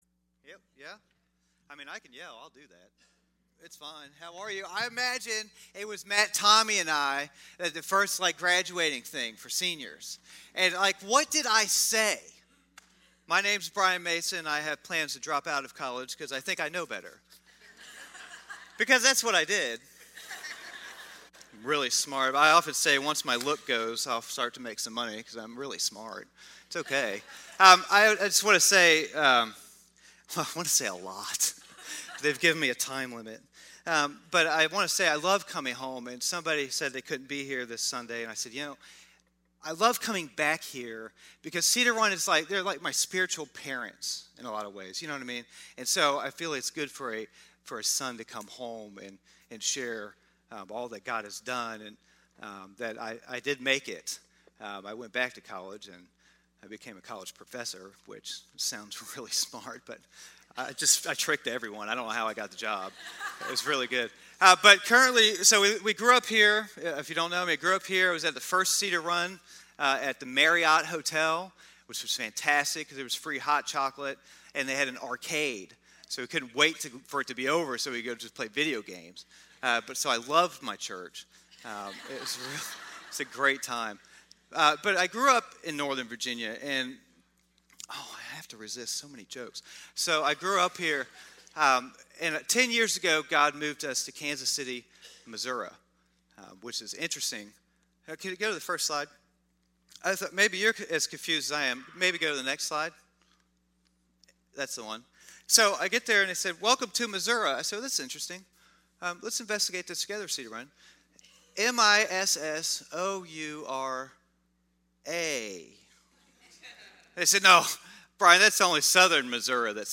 Guest Speaker
10:30 Service
Sermon